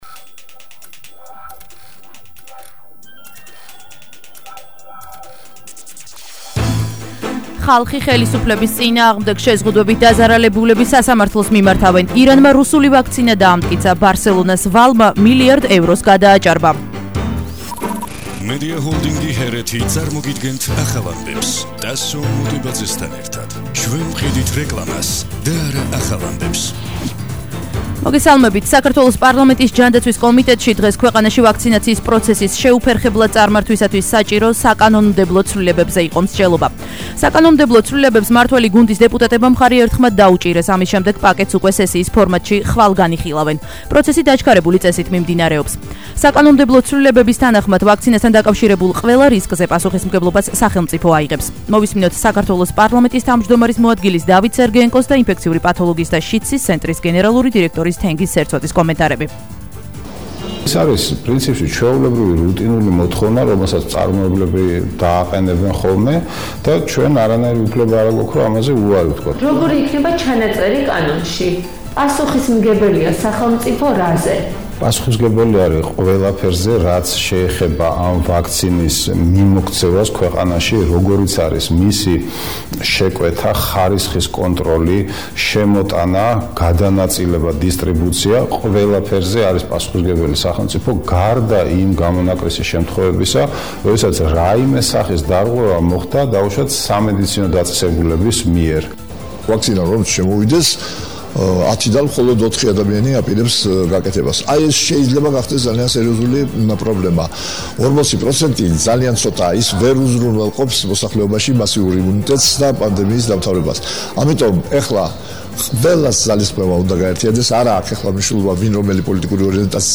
ახალი ამბები 19:00 საათზე –26/01/21